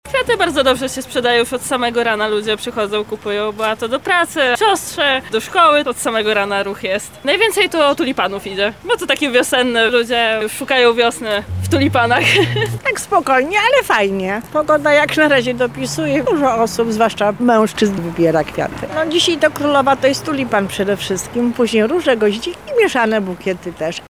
Zapytaliśmy lokalne sprzedawczynie, jakim zainteresowaniem w Święto Kobiet, cieszą się barwne rośliny: